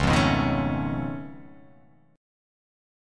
Db+9 chord